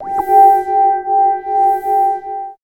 71 SCI-FI -R.wav